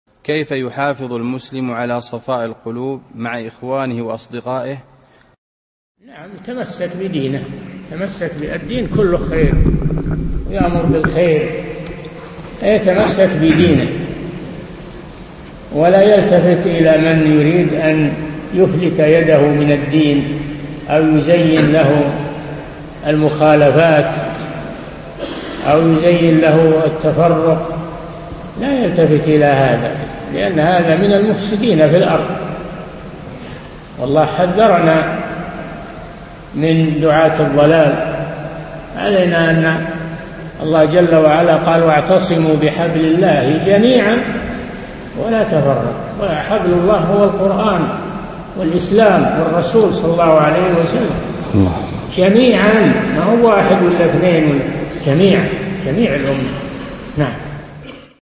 القسم: من مواعظ أهل العلم
Download audio file Downloaded: 566 Played: 855 Artist: للشيخ العلامة صالح الفوزان Title: كيف يحافظ المسلم على صفاء القلوب مع إخوانه Album: موقع النهج الواضح Length: 0:58 minutes (305.49 KB) Format: MP3 Mono 22kHz 32Kbps (VBR)